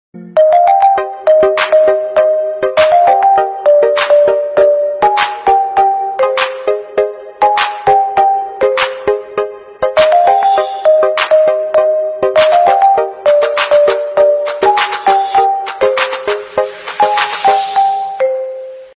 Ringtones Category: Pop - English